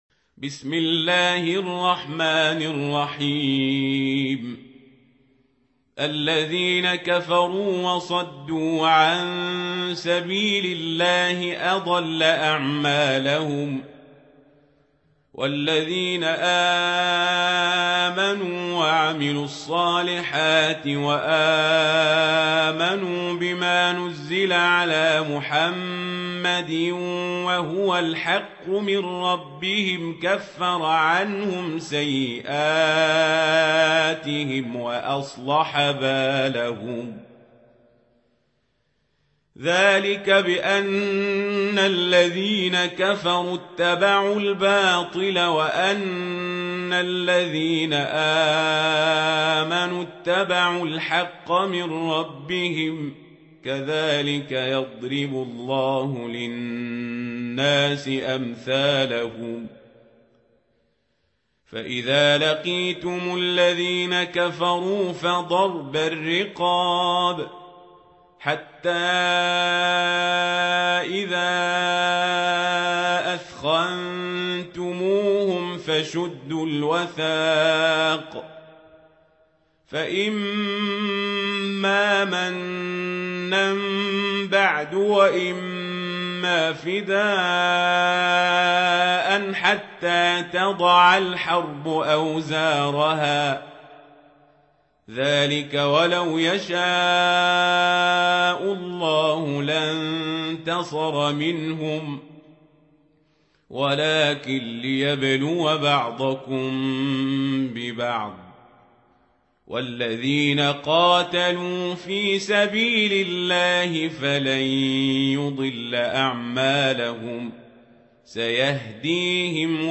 سورة محمد | القارئ عمر القزابري
سورة محمد مدنية عدد الآيات:38 مكتوبة بخط عثماني كبير واضح من المصحف الشريف مع التفسير والتلاوة بصوت مشاهير القراء من موقع القرآن الكريم إسلام أون لاين